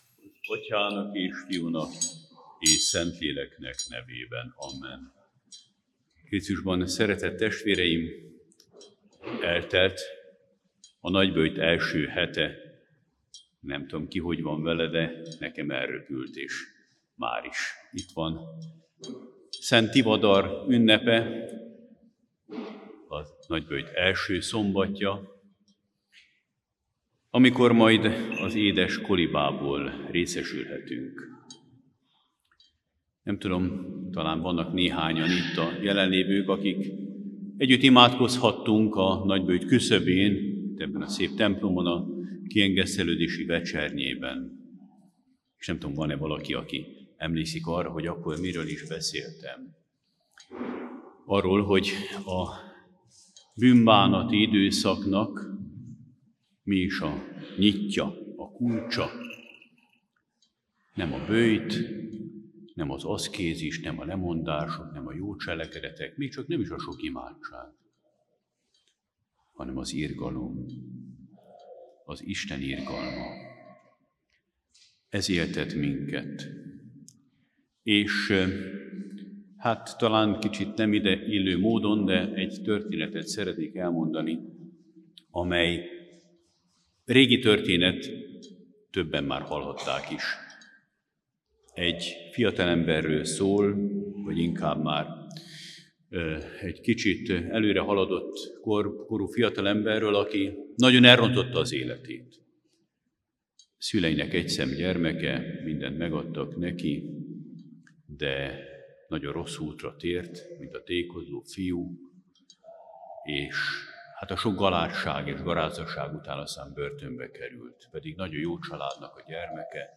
Fülöp metropolita február 20-án, Debrecenben mutatott be Előszenteltek Liturgiáját.
A prédikációt